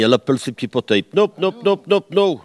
Elle crie pour appeler les canards
Catégorie Locution